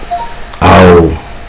wake_word_noise